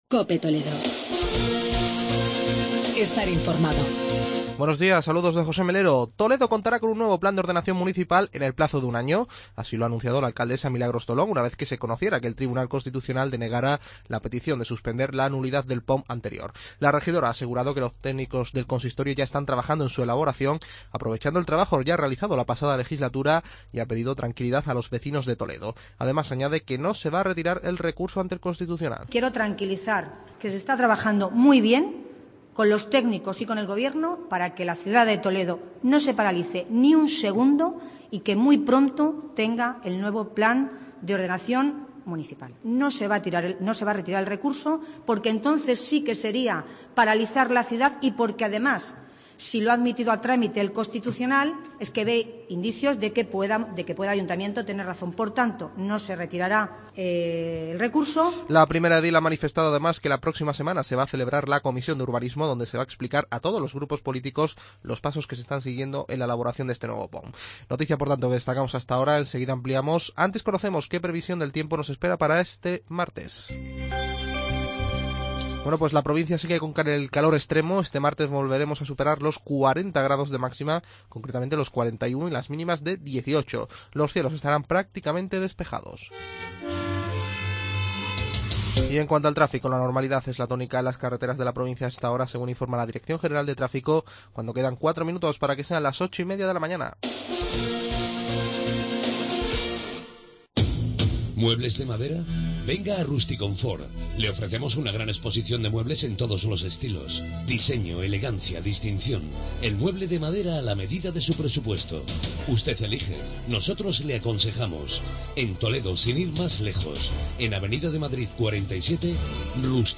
Informativo provincial